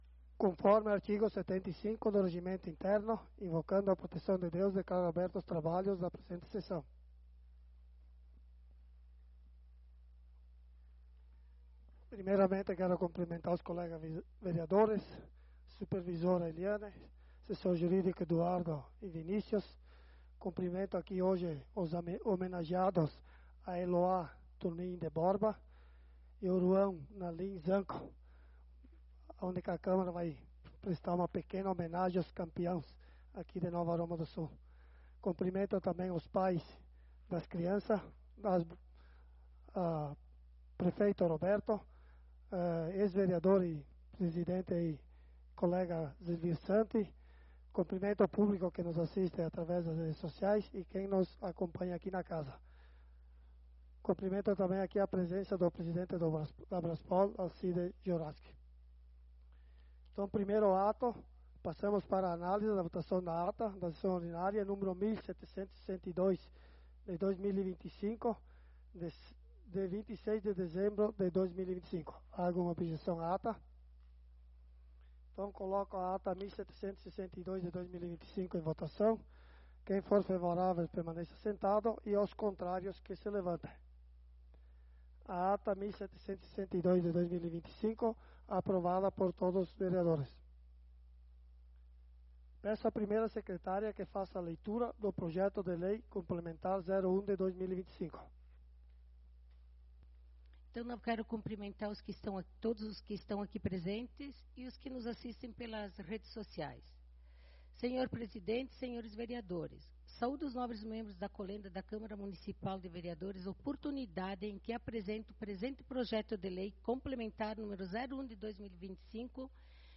Sessão Ordinária do dia 03/12/2025
Câmara de Vereadores de Nova Roma do Sul